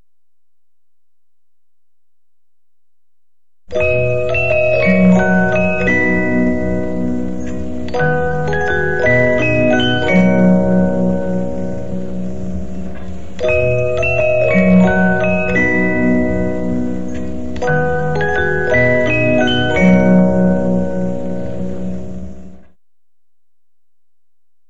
Die ersten Takte des Liedes «Luaget, vo Bärgen u Tal» dienen als Pausenzeichen des Kurzwellensenders Schwarzenburg.